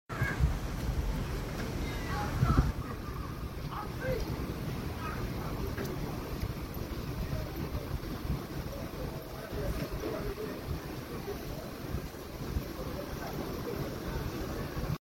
Panasonic clock tower 😊 sound effects free download